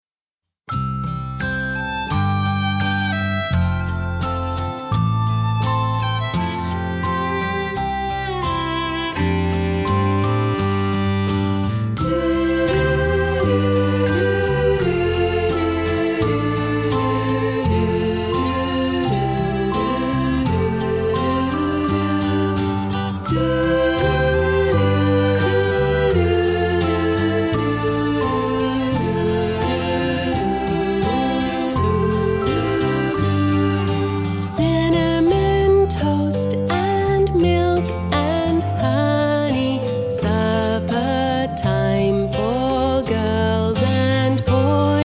mindful and quietly exultant music